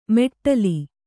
♪ meṭṭali